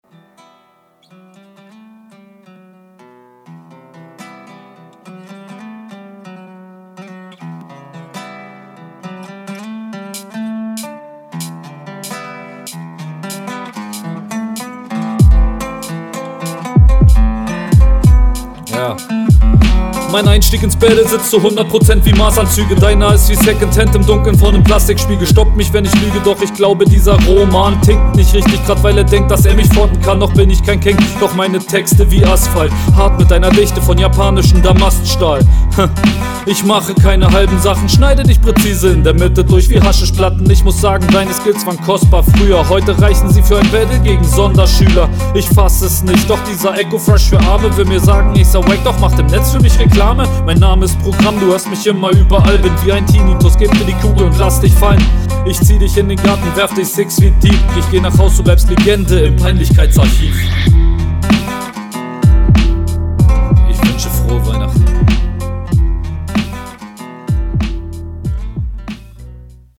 Interessanter Beat.
Flow kinda mid, Mix auch, Punches und Reime gehen klar